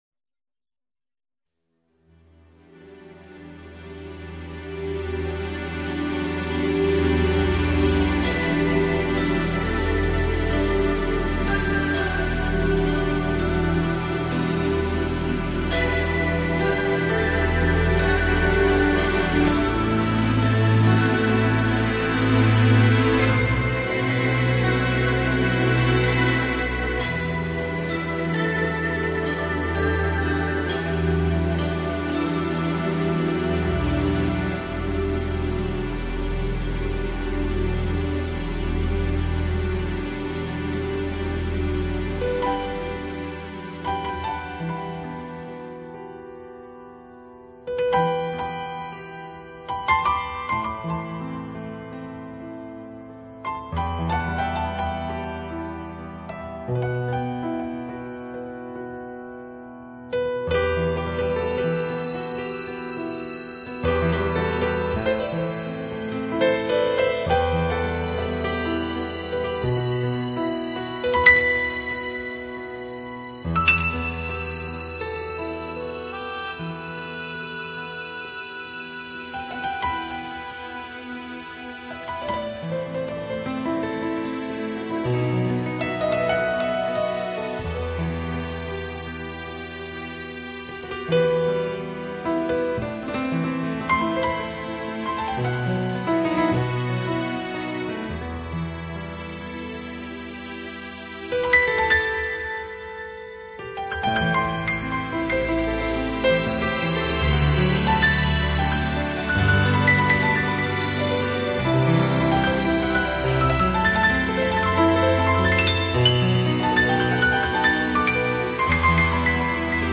类型：New Age
轻柔的吉它、优雅的长笛佐以婉约的钢琴，
坚持真爱的女子在音符流转中闪闪动人，在器乐和鸣中轻轻摇曳。